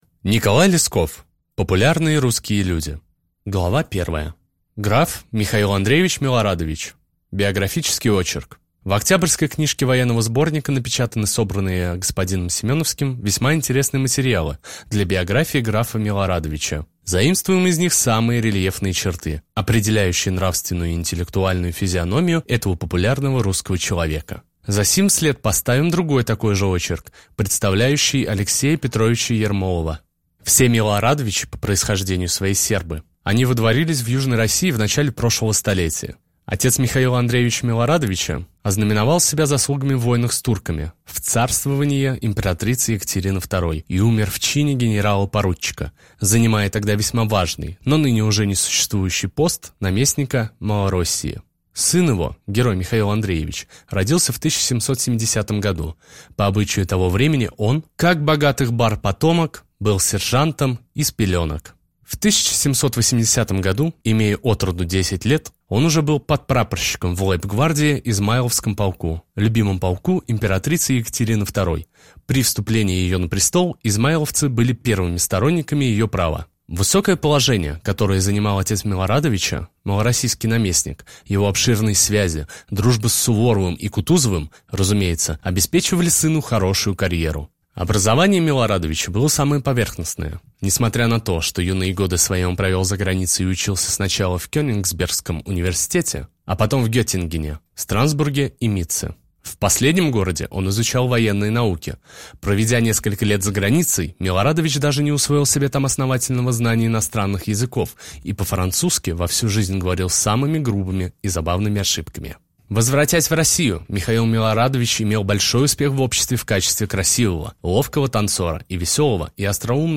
Аудиокнига Популярные русские люди | Библиотека аудиокниг